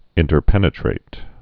(ĭntər-pĕnĭ-trāt)